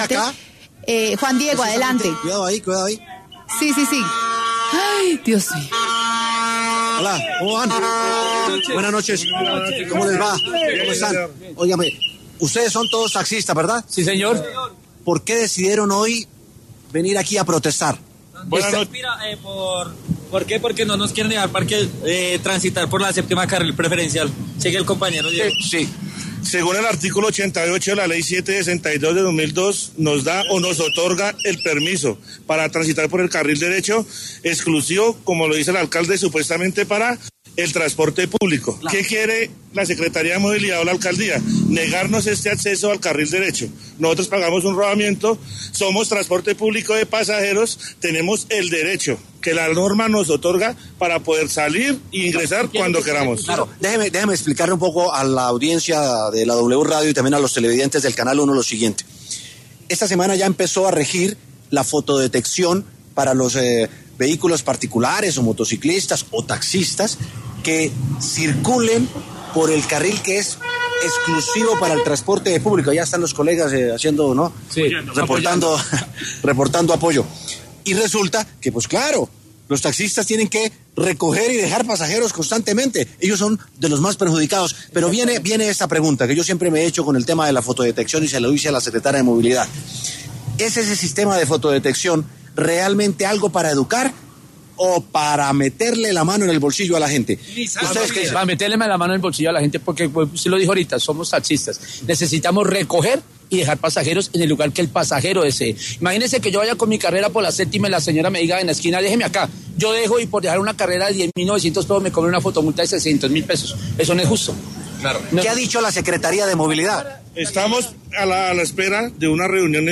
Por esta razón, en los micrófonos de W Sin Carreta se habló con algunos de los taxistas que integraron la protesta, quienes dieron su perspectiva de la coyuntura.